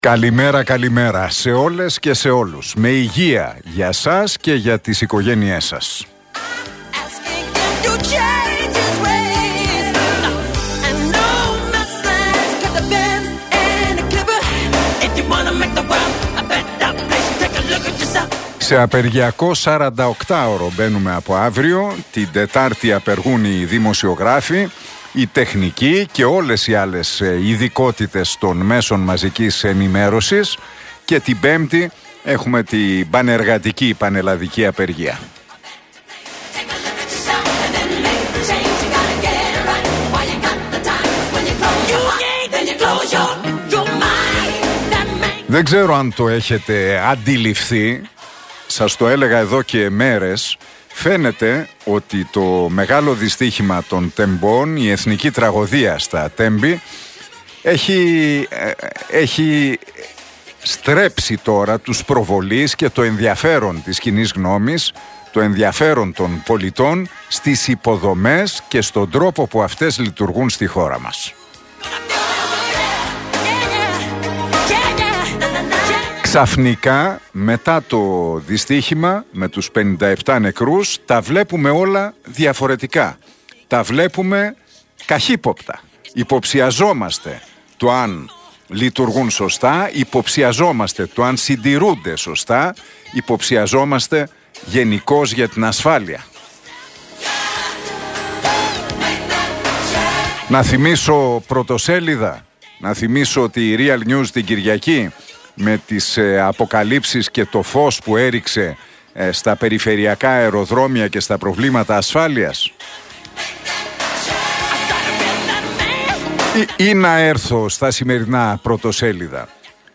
Ακούστε το σχόλιο του Νίκου Χατζηνικολάου στον RealFm 97,8, την Τρίτη 14 Μαρτίου 2023.